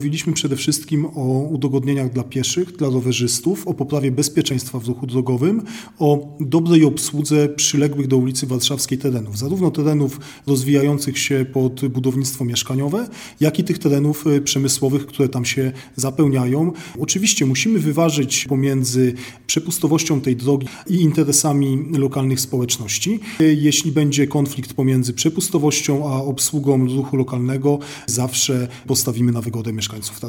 Jak więc ma wyglądać nowa droga? Komentuje wiceprezydent miasta, Mateusz Tyczyński: